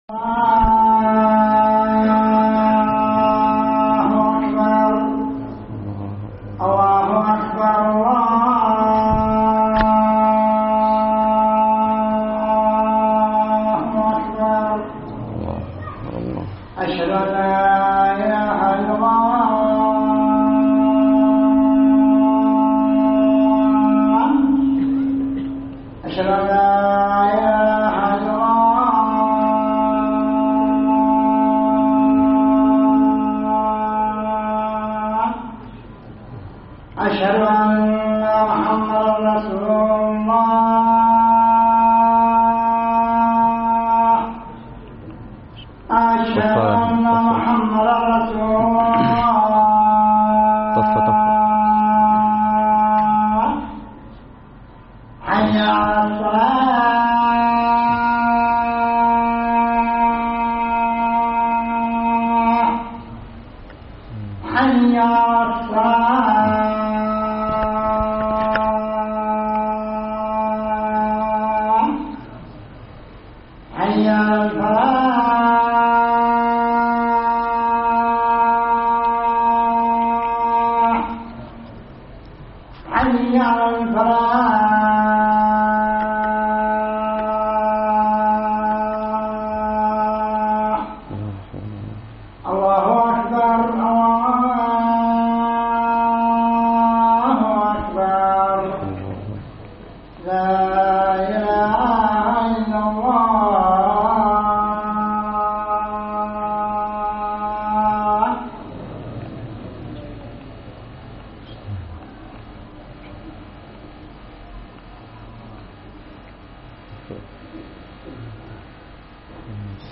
خطب الجمعة
ألقيت بدار الحديث السلفية للعلوم الشرعية بالضالع في 4 ذو الحجة 1436هــ